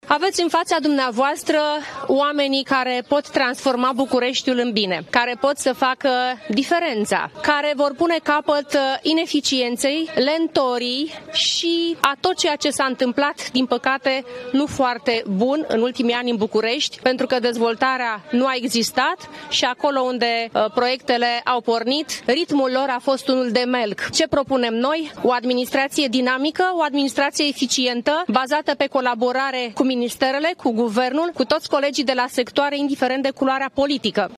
Gabriela Firea și-a depus candidatura pentru un nou mandat la Primăria Capitalei.